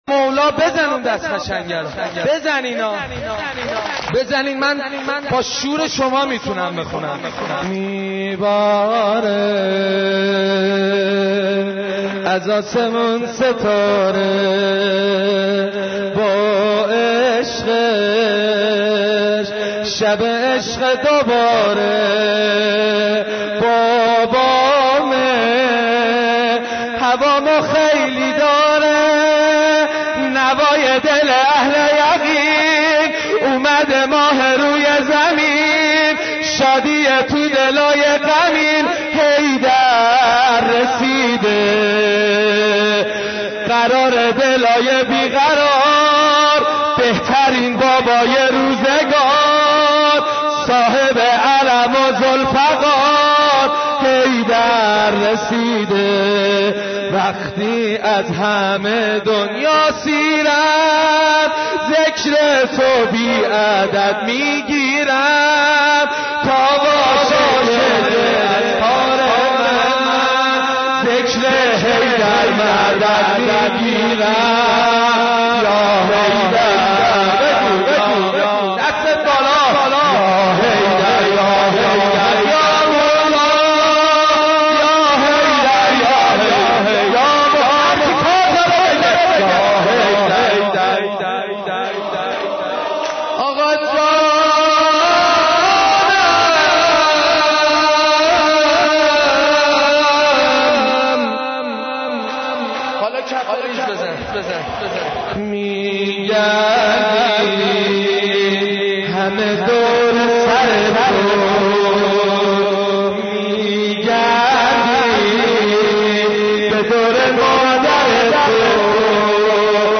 مرثیه خوانی